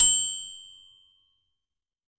end_ding.ogg